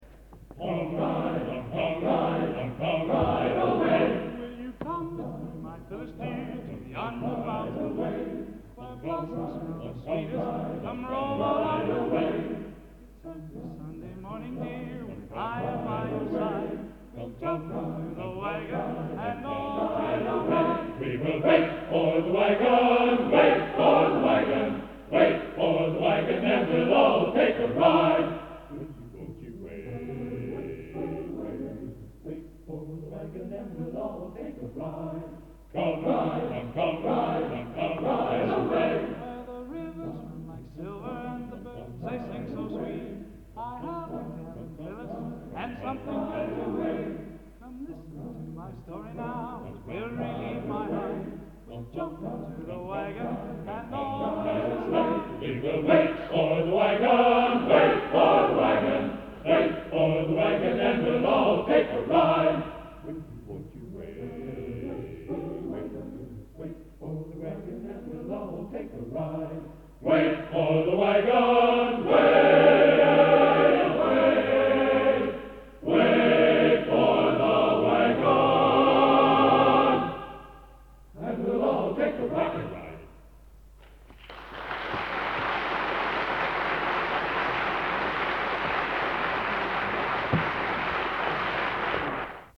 Collection: Plymouth, England
Location: Plymouth, England